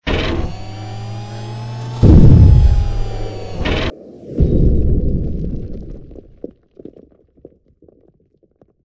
rocketgroundin.ogg